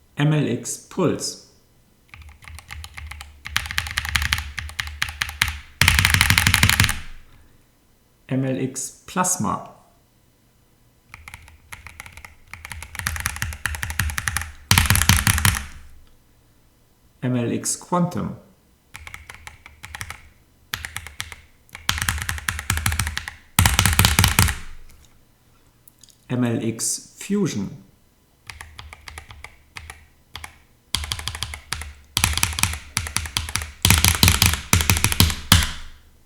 Es „thockt“ bei Corsair klarer, tiefer und dumpfer, immer noch hörbar, aber ohne die durchdringenden hohen Resonanzen in einer Sattheit, die Endorfy fehlt. Thocky-Switches produzieren also ein relativ klares Geräusch.
Die Plasma- und Speed-Taster sind weniger ploppig am Anschlag und generieren ein normales, dezenteres „Tack“.